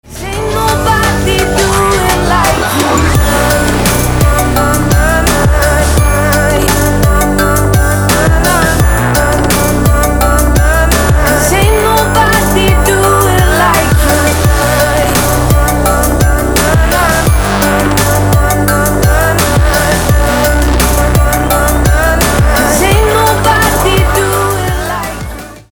• Качество: 256, Stereo
Electronic
EDM
club
future bass